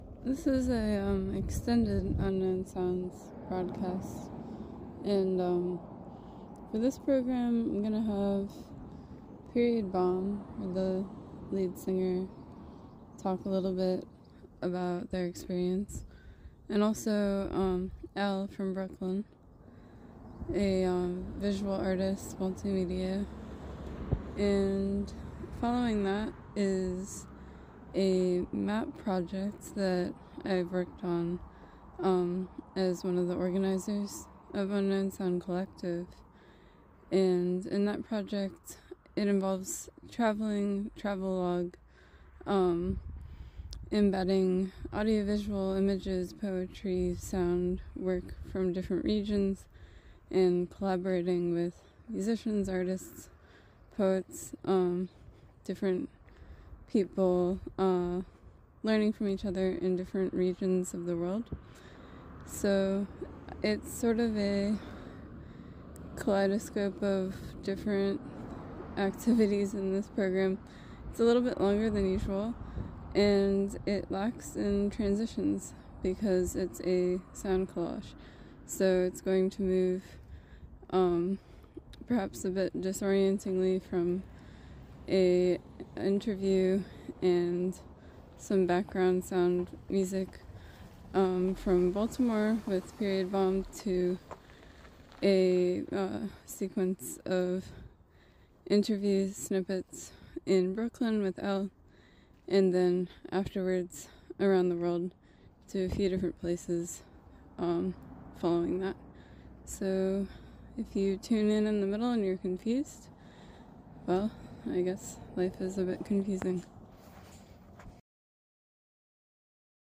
Extended episode includes materials from shows